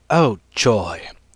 vs_fScarabx_sick.wav